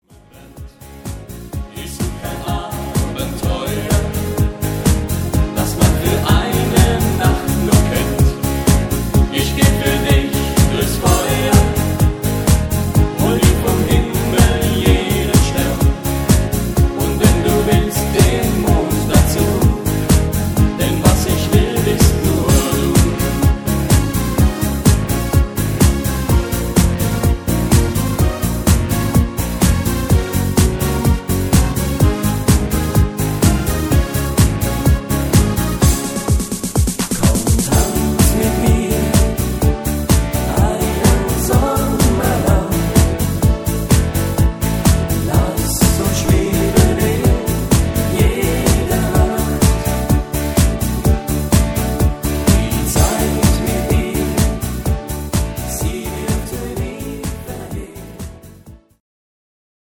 Rhythmus  Countryfox
Art  Deutsch, Party Hits, Schlager 2000er